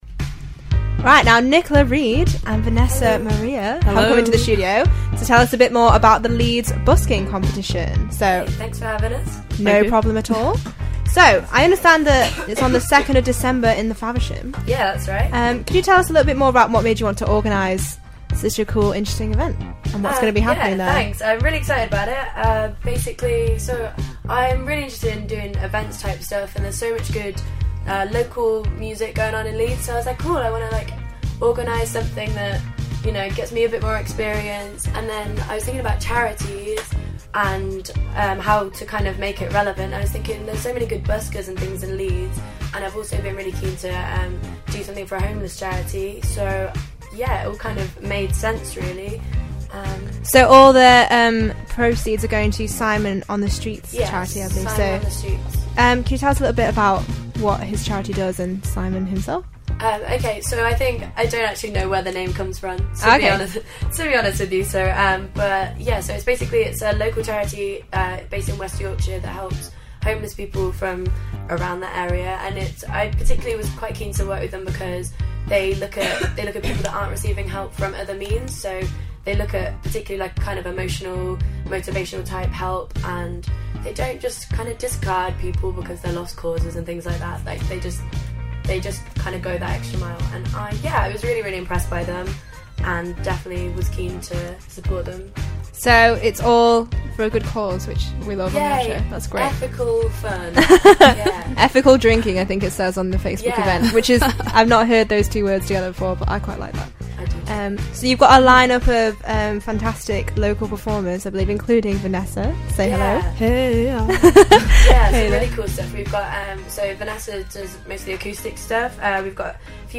Interview
Live Session